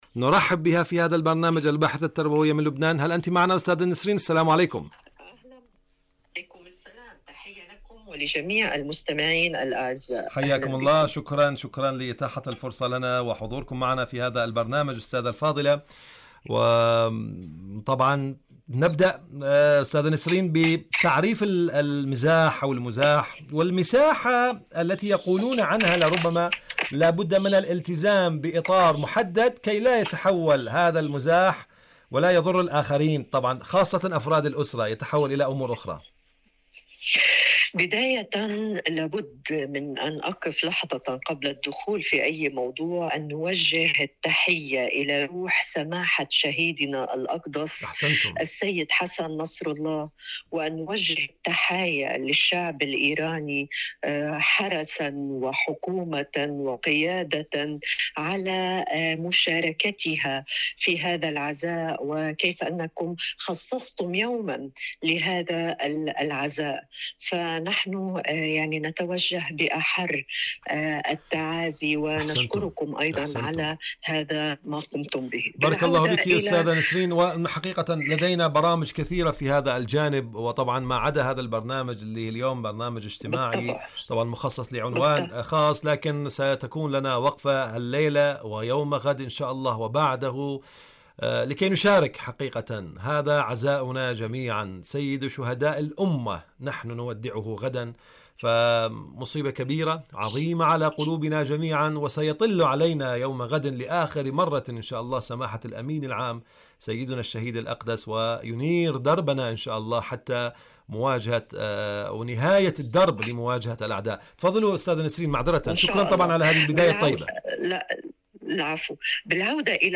برنامج معكم على الهواء مقابلات إذاعية